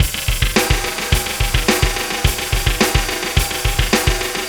Pulsar Beat 28.wav